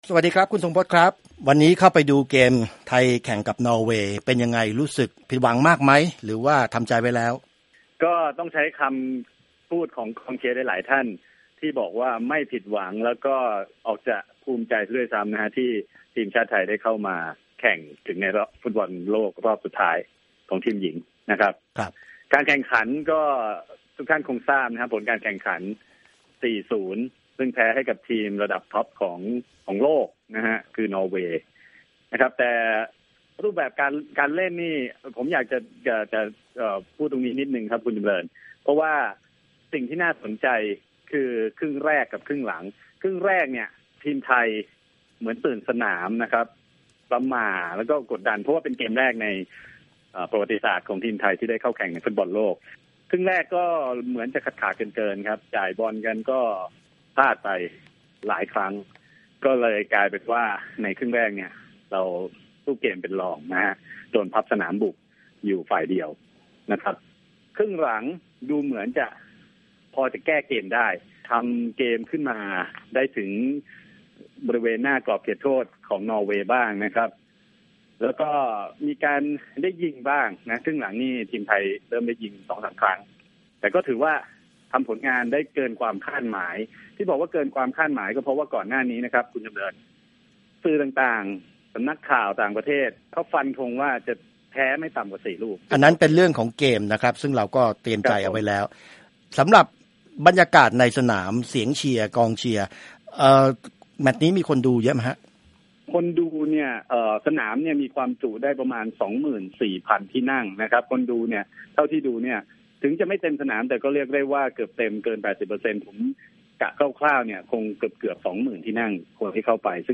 กองเชียร์ไทยส่งเสียงเชียร์แรงไม่ตกในนัดประวัติศาสตร์แม้ทีมหญิงไทยพ่ายนอร์เวย์ขาด 4-0
ด้านกองเชียร์ชาวไทยที่เข้ามาชมนัดนี้กว่าหนึ่งพันคนต่างส่งเสียเชียร์ไม่หยุดตลอด 90 นาที เพื่อให้กำลังใจแก่ทีมหญิงไทย หลายคนร้องรำทำเพลงอย่างสนุกสนานโดยไม่คิดถึงผลการแข่งขัน